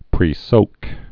(prē-sōk)